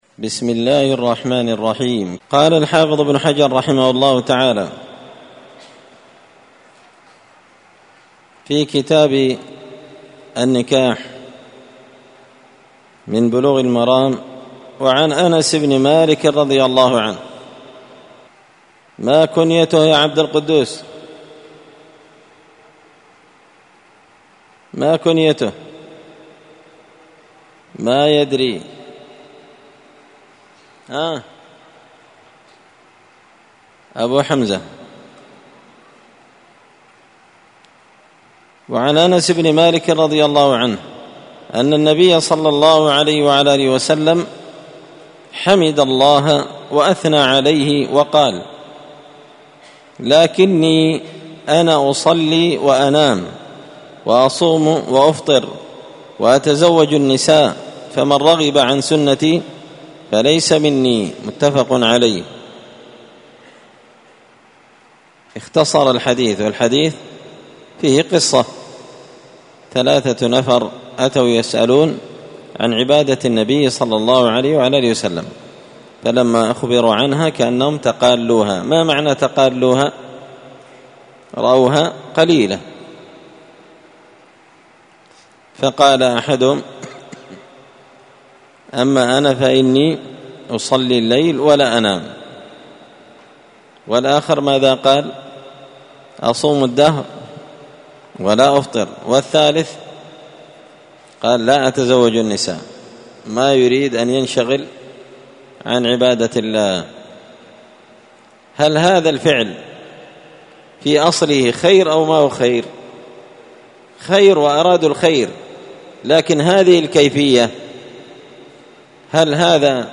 مسجد الفرقان_قشن_المهرة_اليمن